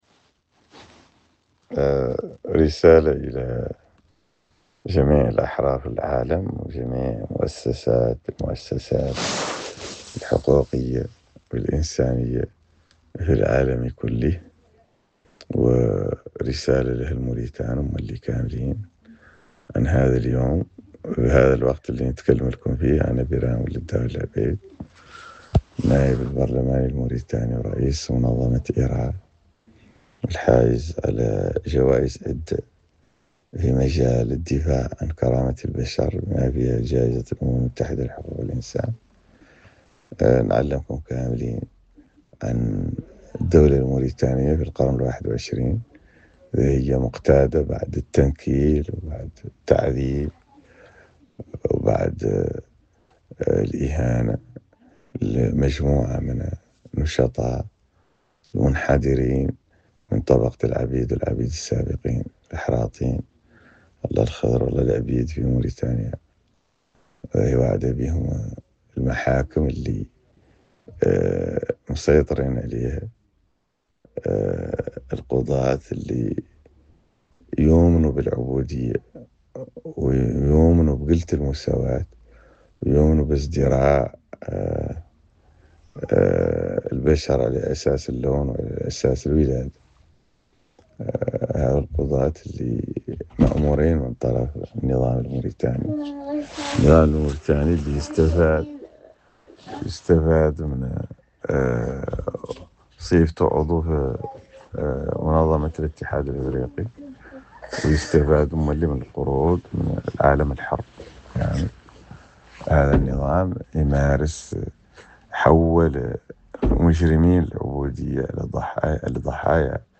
Message audio de Biram Dah Abeid : Répressions des militants anti esclavagistes en Mauritanie